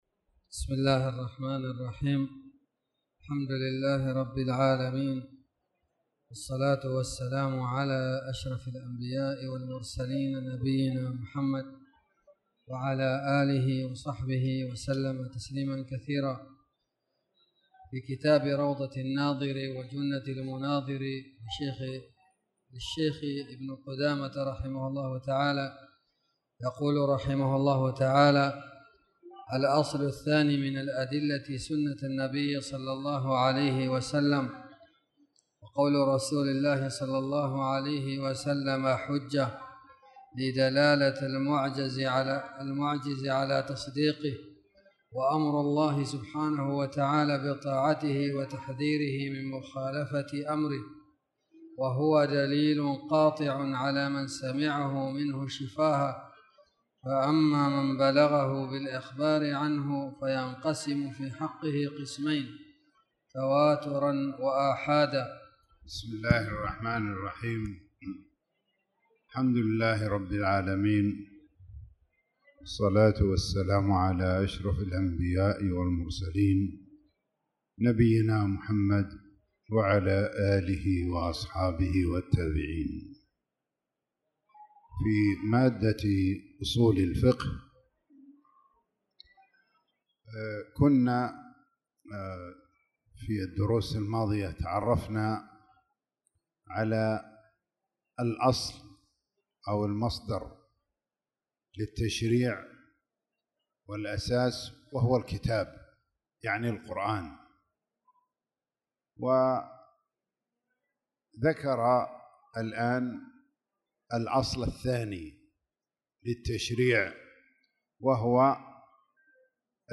تاريخ النشر ١٧ شعبان ١٤٣٧ هـ المكان: المسجد الحرام الشيخ